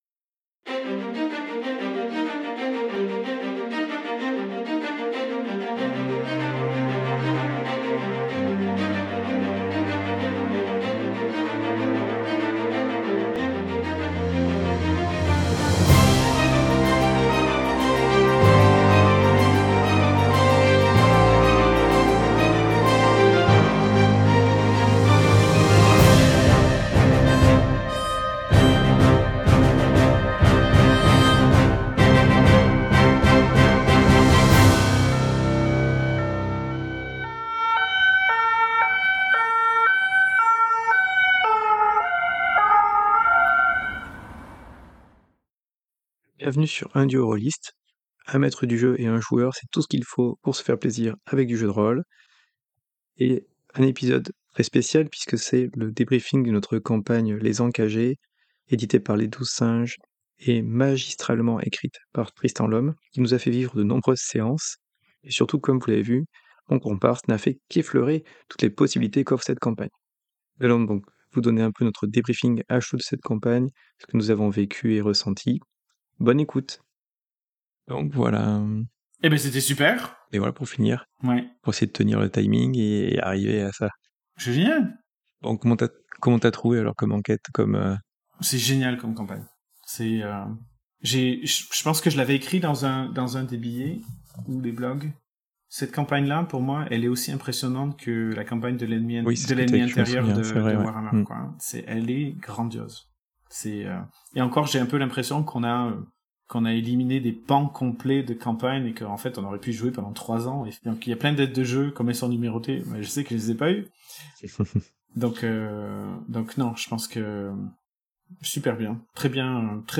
Gendarmerie, sirène extérieure véhicule sur La Sonothèque